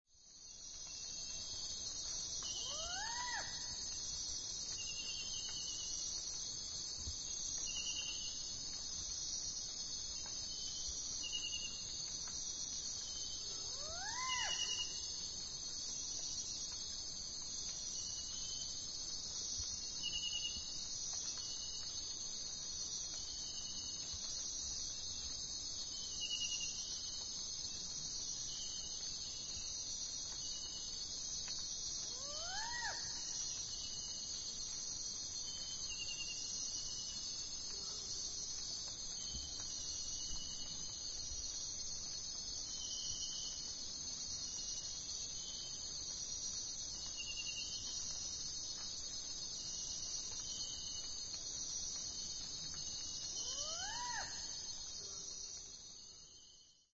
Night in the Amazon rainforest.
Some kind of blood-freezing spine chilling owl, or an Amazon banshee from hell.
screamingbanshee.mp3